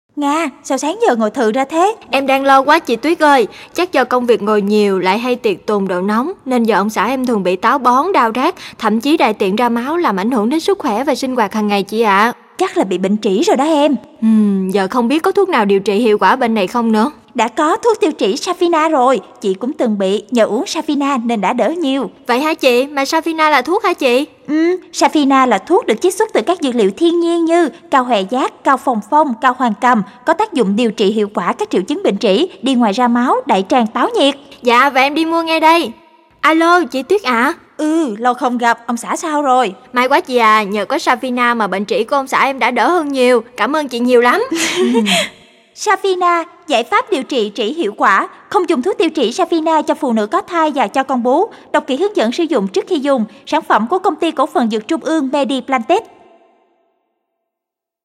Trên đài phát thanh cho công chúng